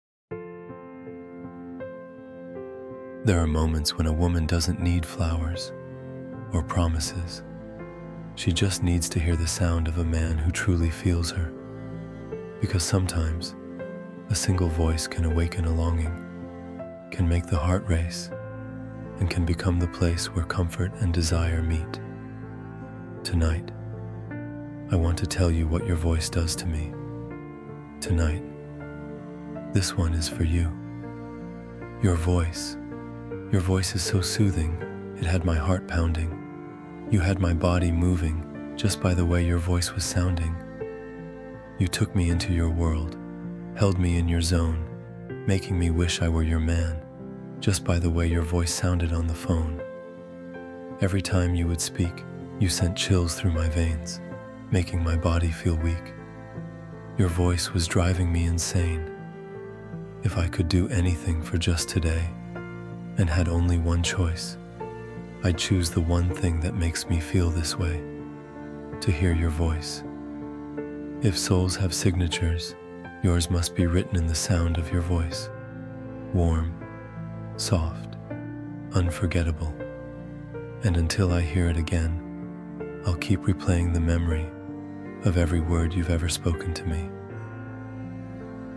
your-voice-romantic-poem-for-her.mp3